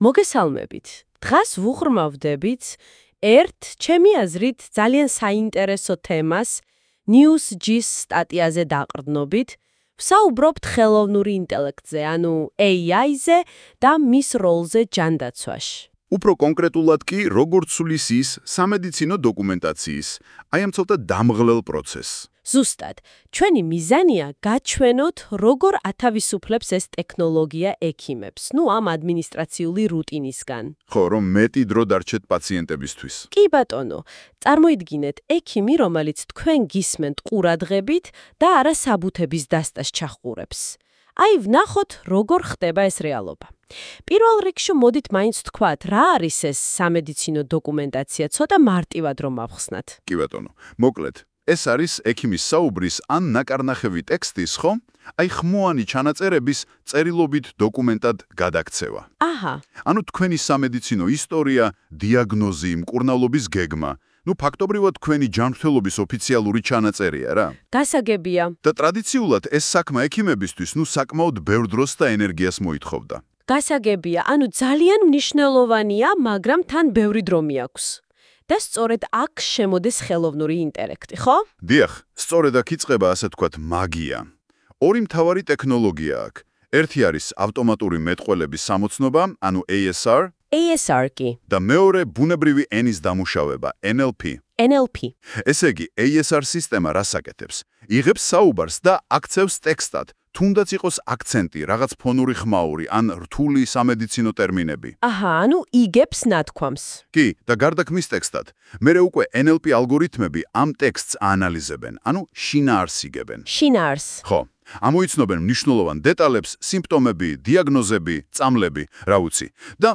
აქ შეგიძლიათ მოუსმინოთ ამ სტატიის აუდიო ვარიანტს, რომელიც  მთლიანად გენერირებულია ხელოვნური ინტელექტის მეშვეობით ავტომატურად როგორც პოდკასტი.